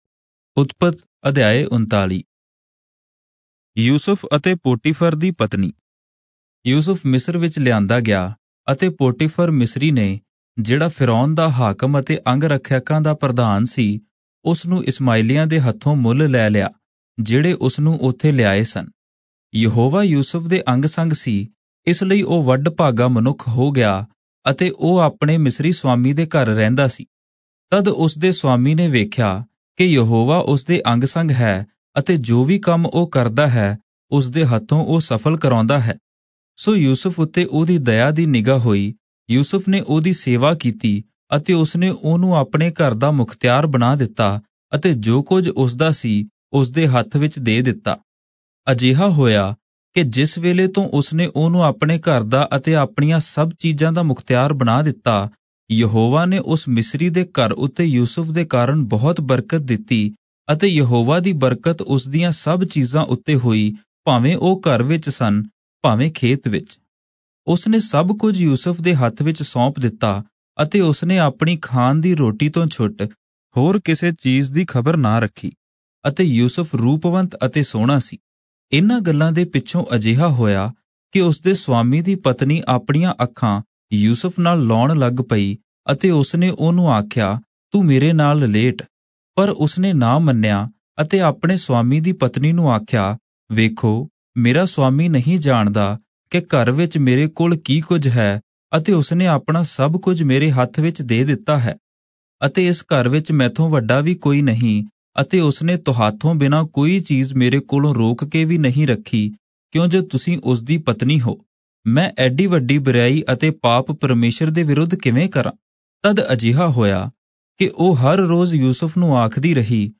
Punjabi Audio Bible - Genesis 27 in Irvpa bible version